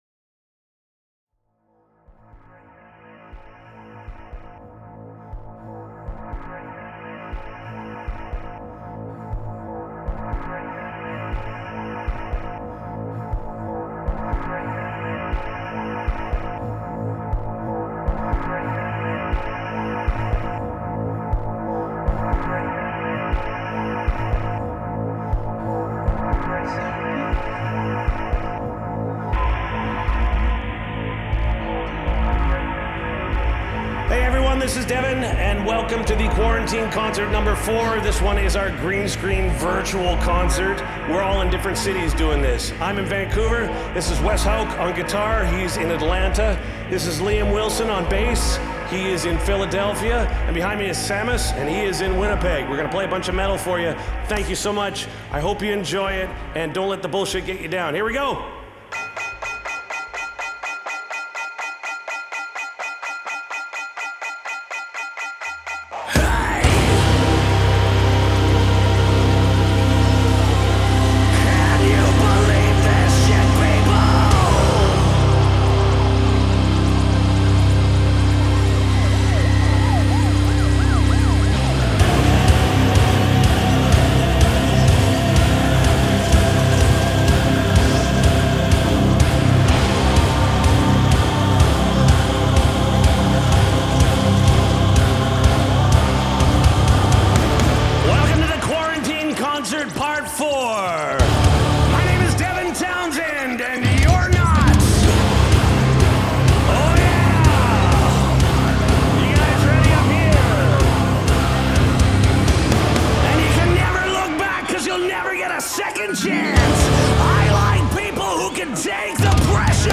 Genre : Progressive Rock, Progressive Metal